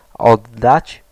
Ääntäminen
IPA : /dəʊneɪt/